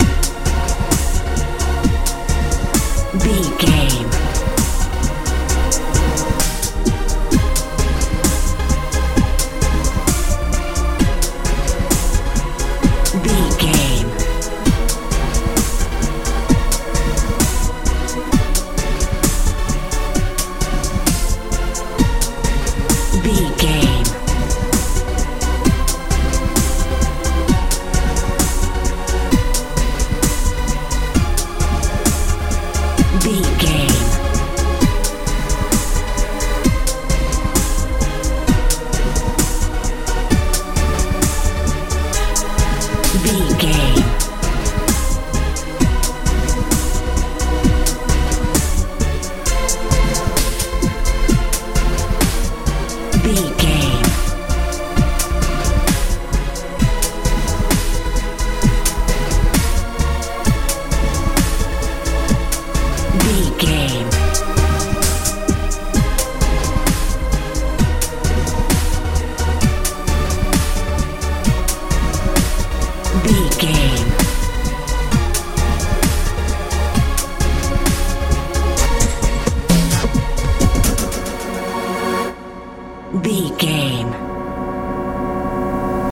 modern dance
Aeolian/Minor
B♭
futuristic
powerful
bass guitar
synthesiser
drums
80s